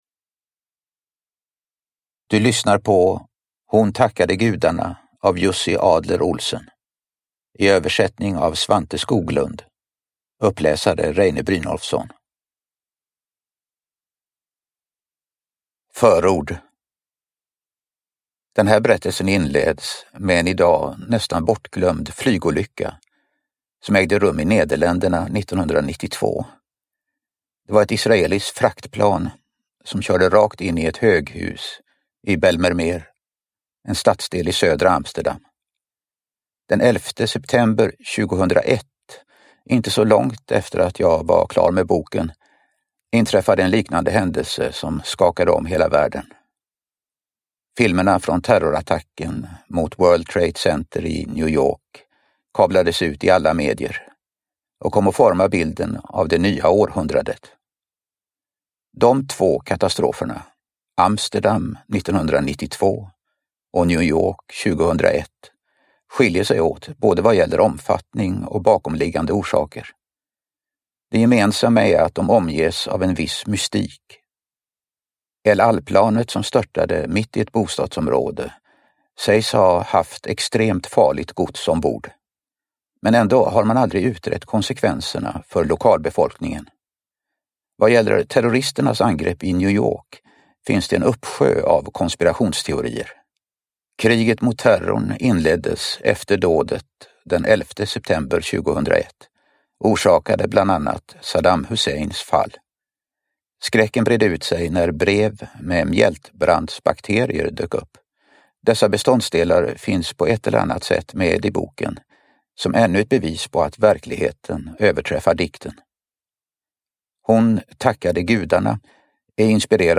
Hon tackade gudarna – Ljudbok – Laddas ner
Uppläsare: Reine Brynolfsson